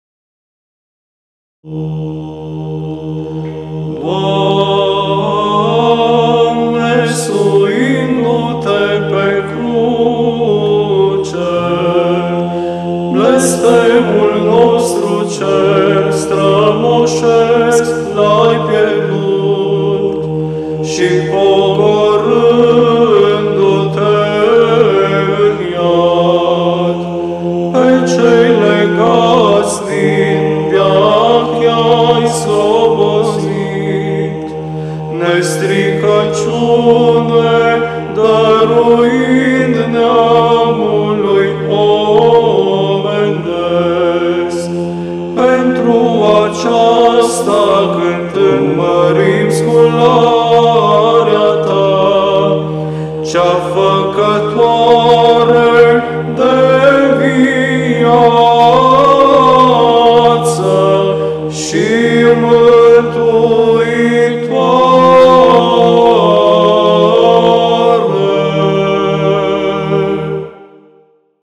Glasurile bisericești – varianta de tradiție bizantină TRIFON LUGOJAN
GLAS IV
12.-Glas-IV-Stihoavna.mp3